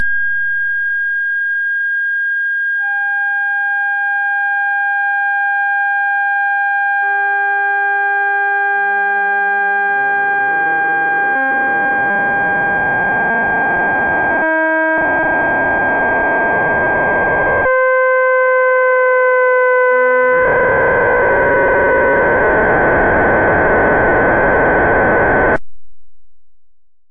This permits the bifurcations and chaos to be heard with a loudspeaker as the variable resistor (value 1/A) is adjusted from 1000 to 2000 ohms.
A real circuit using parts available for about $10 from Radio Shack has also been constructed and tested.
The digitized signal is actually the integral of x so as to accentuate the low frequencies during the period doublings.  You should be able to hear the period-1, period-2, period-4, and period-8 bifurcations, chaos, the period-6 window, chaos again, the period-5 window with doubling to period-10, and chaos again.